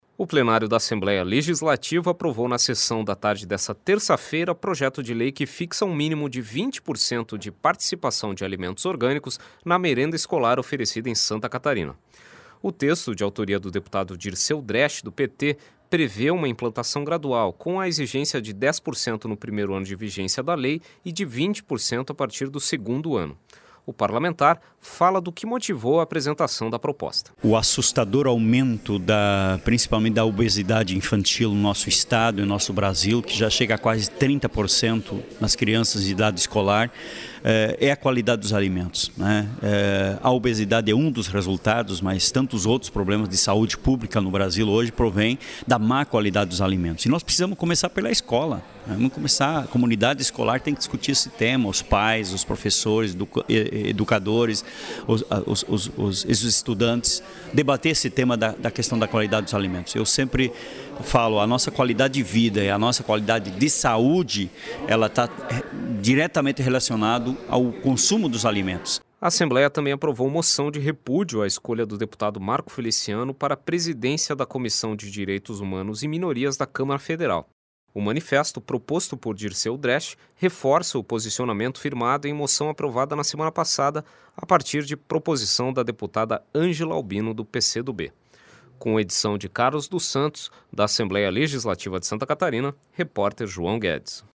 Entrevista com: deputado Dirceu Dresch (PT).